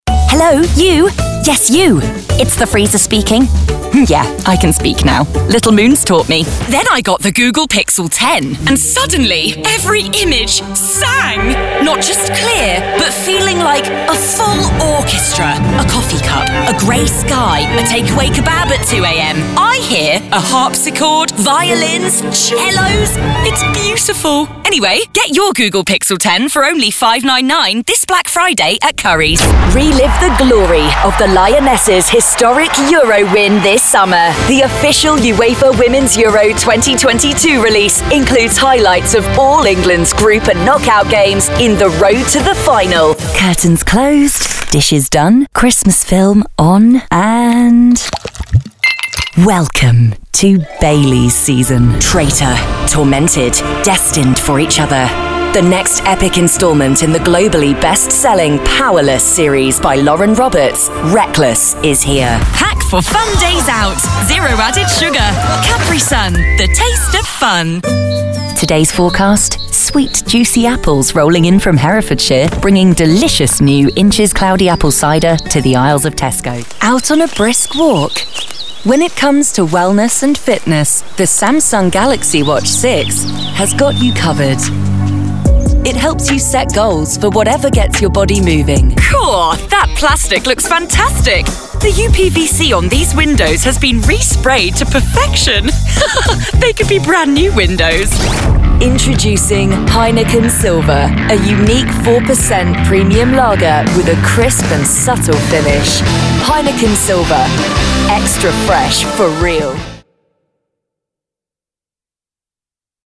Narration
Commercial
Voice of God